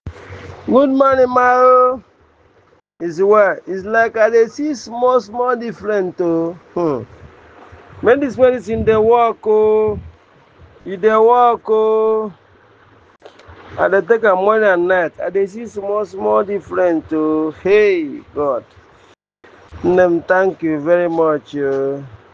Verified Customer
Testimonial 5